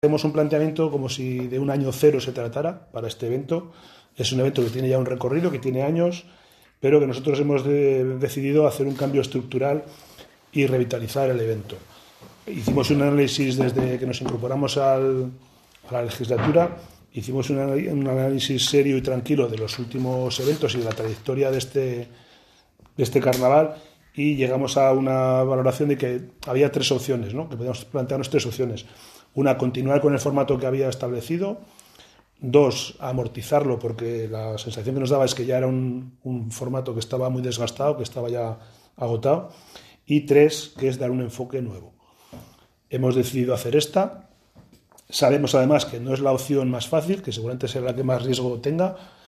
PP, VINEA y C´S han comparecido, como equipo de gobierno del Ayuntamiento de Haro, para defender la decisión de intentar revitalizar el evento.
La alcaldesa, Guadalupe Fernández, en la misma línea, ha insistido en que era necesario un nuevo planteamiento para la actividad y si con el balance que se haga, en su momento, se cree que no se han cumplido los objetivos, se le dará una nueva vuelta «para no perderlo».